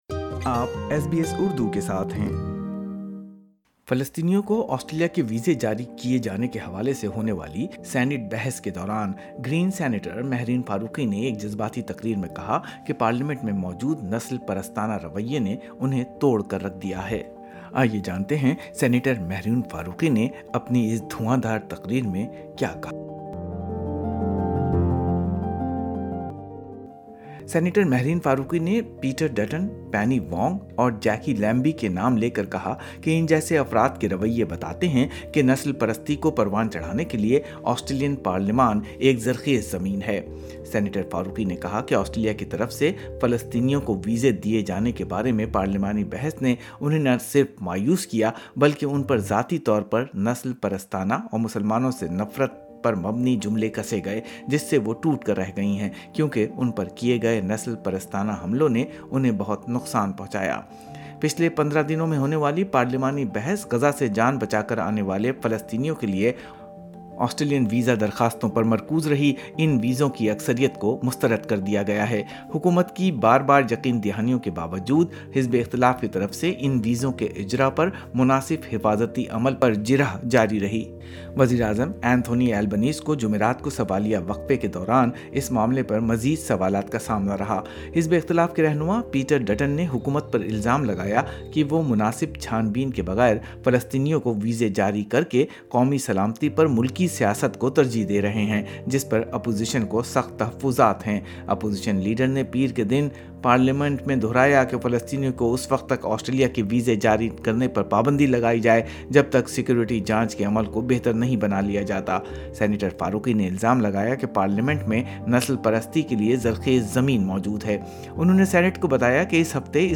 فلسطینیوں کو ویزے جاری کرنے کے حوالے سے ہونے والی سینٹ بحث کے دوران گرین سنیٹر مہرین فاروقی نےایک جذباتی تقریر میں کہا کہ پارلیمنٹ میں موجود نسل پرستانہ رویے نے انہیں توڑ کر رکھ دیا ہے۔سینیٹر مہرین فاروقی نے پیٹر ڈٹن، پینی وونگ اور جیکی لیمبی کے نام لے کر کہا کہ ان جیسے افراد کے رویے بتاتے ہیں کہ نسل پرستی کو پروان چڑھانے کے لئے آسٹریلین پارلیمنٹ ایک زرخیز زمین ہے۔